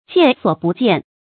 見所不見 注音： ㄐㄧㄢˋ ㄙㄨㄛˇ ㄅㄨˊ ㄐㄧㄢˋ 讀音讀法： 意思解釋： 見「見所未見」。